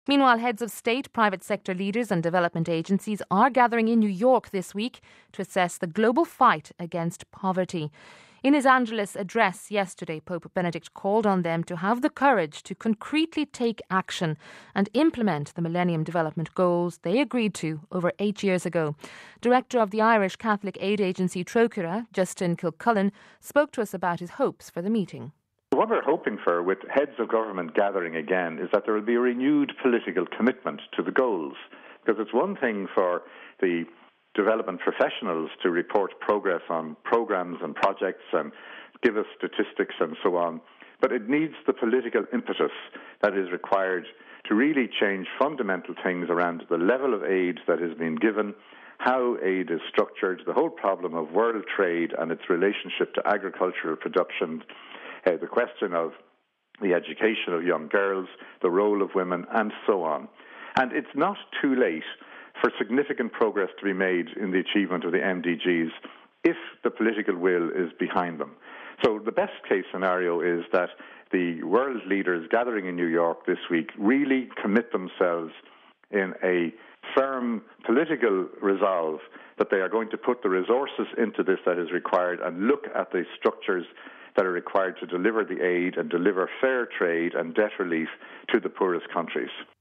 spoke to us about his hopes for the meeting.